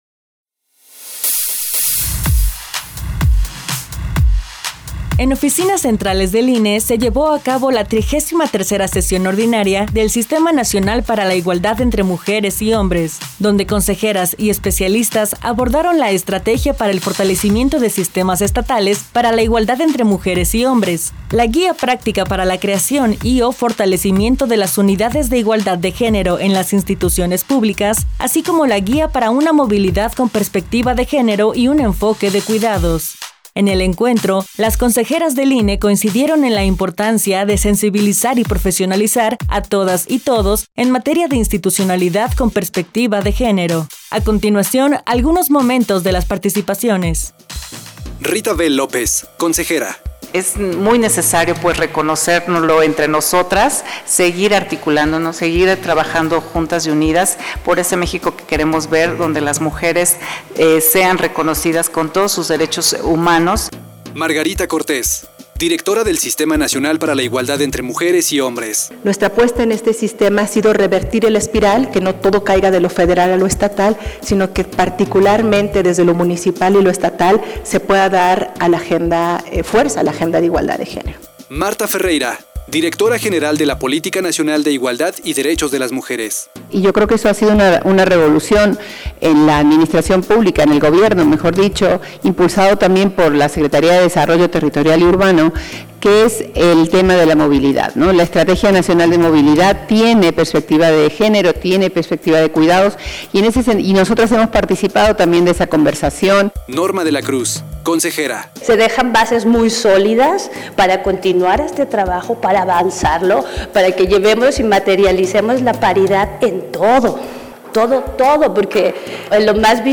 Nota de audio sobre la Trigésima Tercera Sesión Ordinaria del Sistema Nacional para la Igualdad entre Mujeres y Hombres, 2 de agosto de 2024